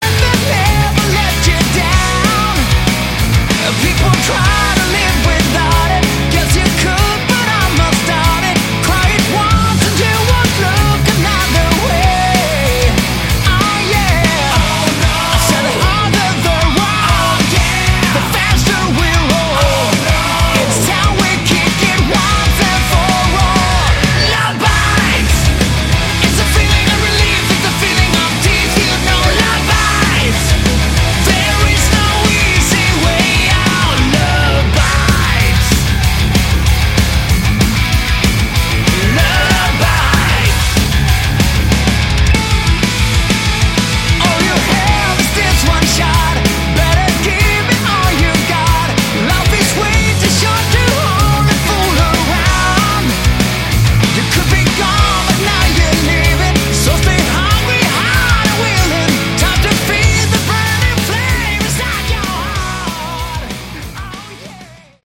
Category: Hard Rock
lead vocals, guitar
lead guitars
drums
bass